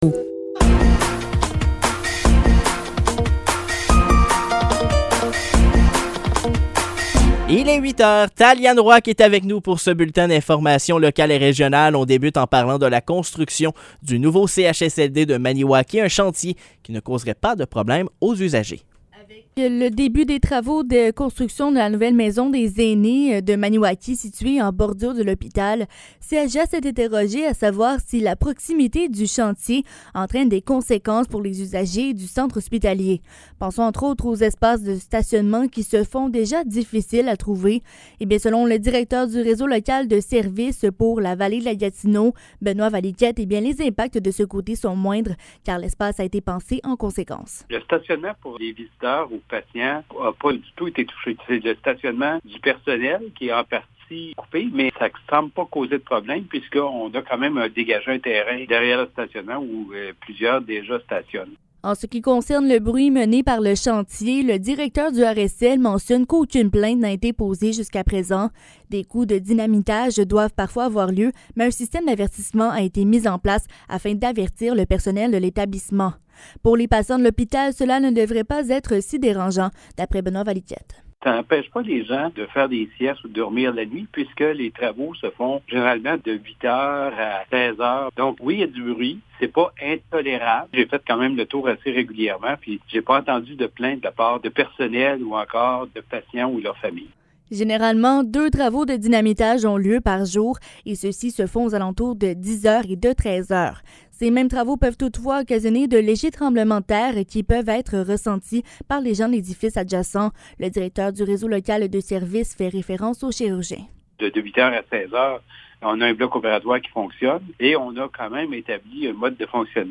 Nouvelles locales - 22 août 2023 - 8 h